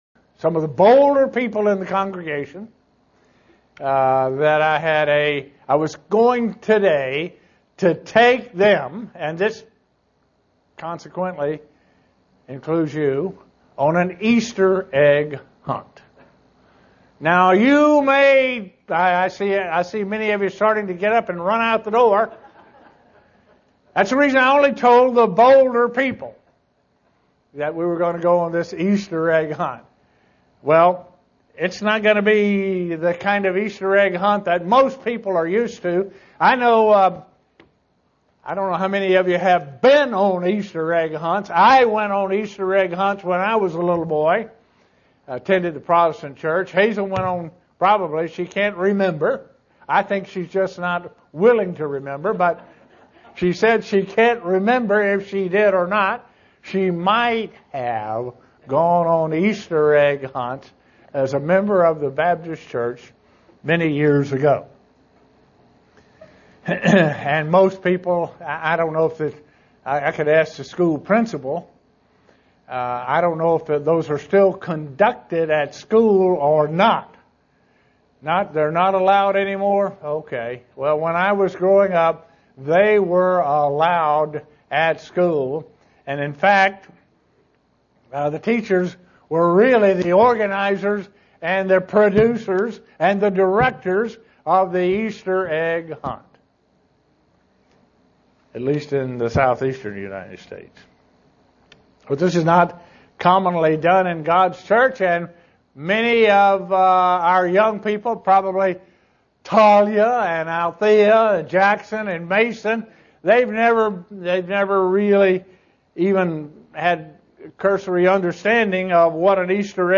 Given in Buffalo, NY
SEE VIDEO BELOW UCG Sermon Studying the bible?